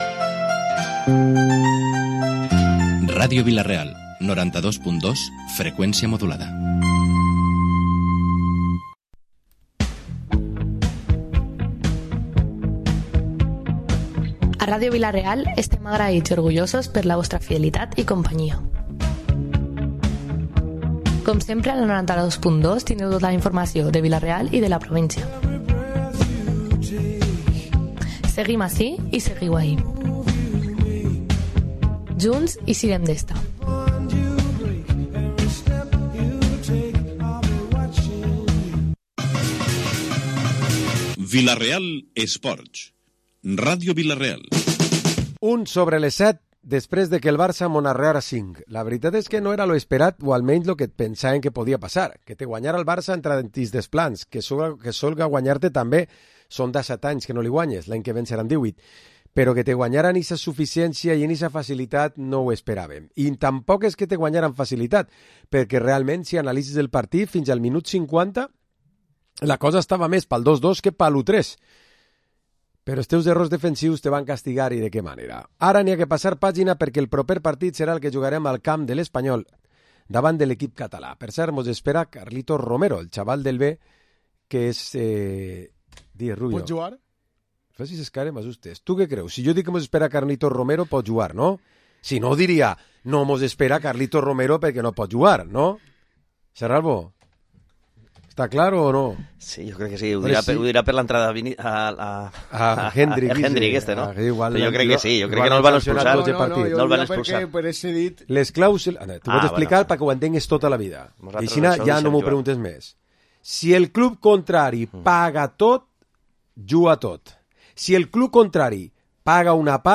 Programa esports tertúlia dilluns 23 de setembre